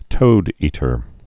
(tōdētər)